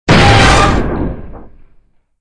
collision_shipasteroid1.wav